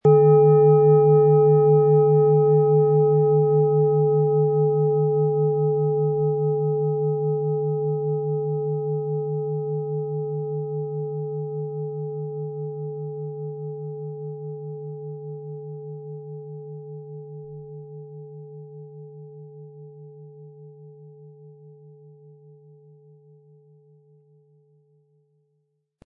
Sie sehen und hören eine von Hand gefertigt Saturn Klangschale.
• Mittlerer Ton: Neptun
PlanetentöneSaturn & Neptun
MaterialBronze